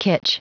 Prononciation du mot kitsch en anglais (fichier audio)
Prononciation du mot : kitsch